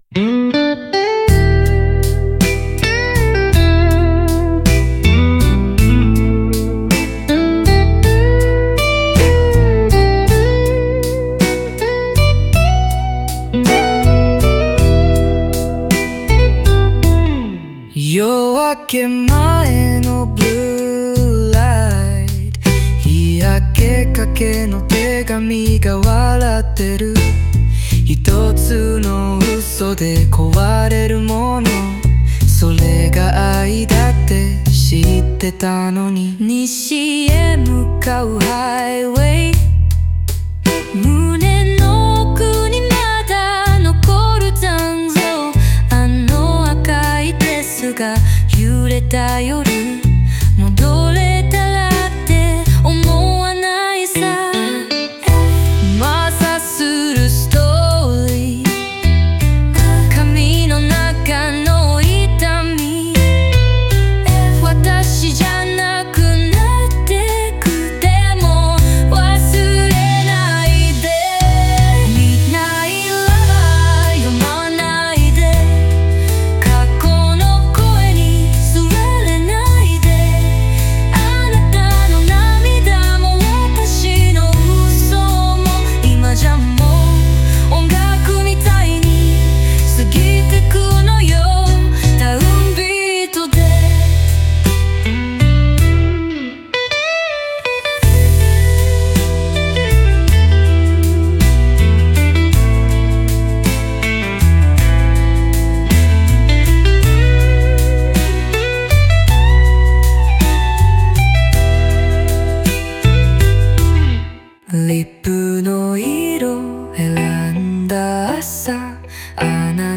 オリジナル曲♪
60年代のソウルやポップスのテイストで、男女の掛け合いを通じて「届かない声」「消えた夜」を音楽的に再構築。